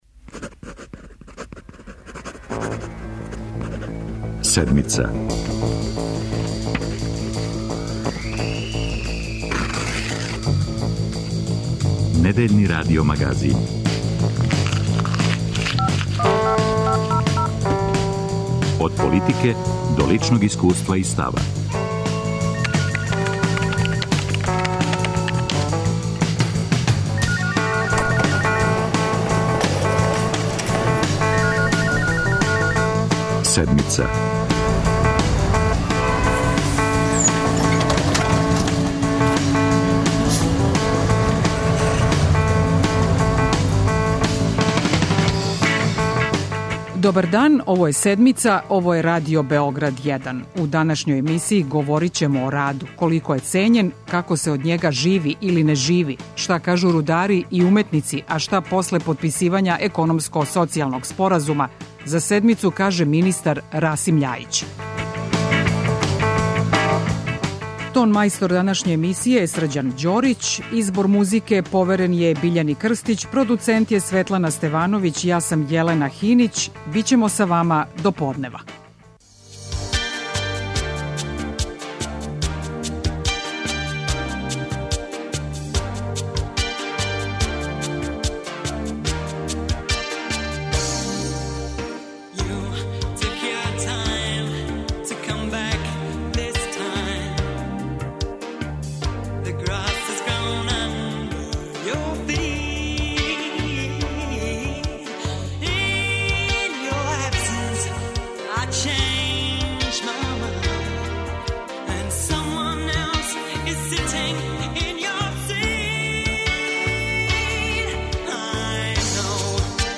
О вредновању рада у транзиционој Србији и о најновијим мерама Владе говори министар Расим Љајић.